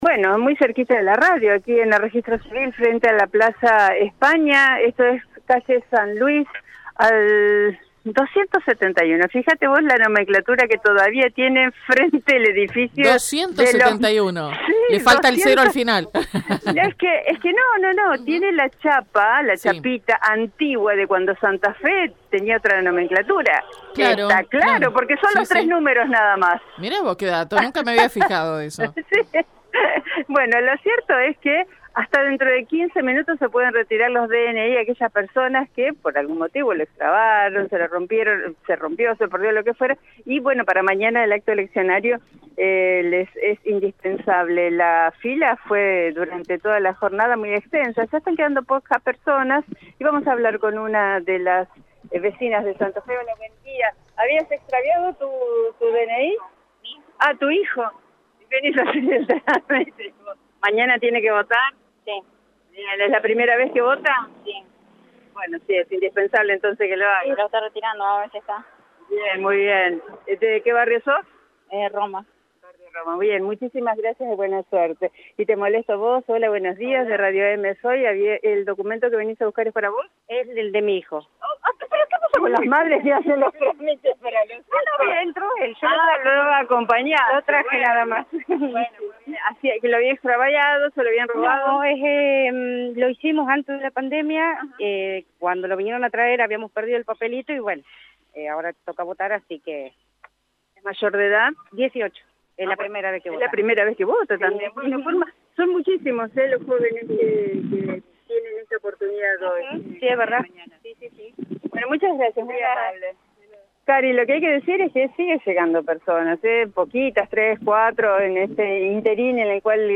Movil Radio EME